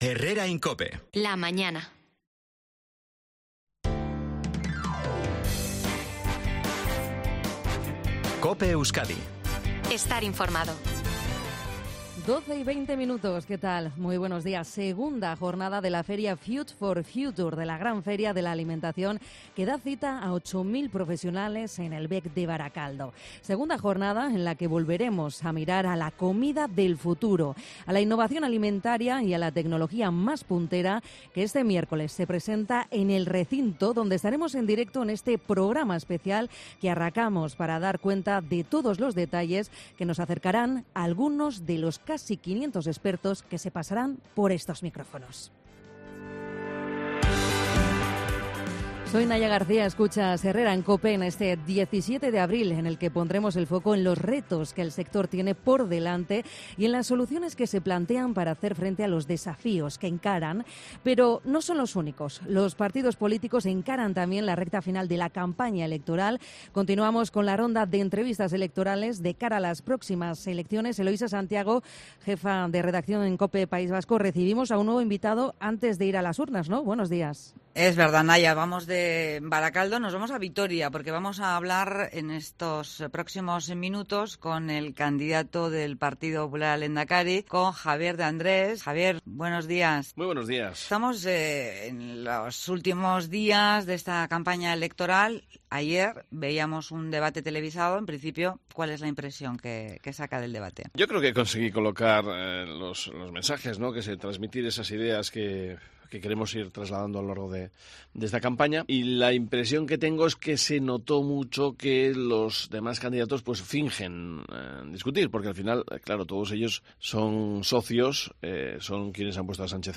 Javier de Andrés (PP), entrevistado en COPE Euskadi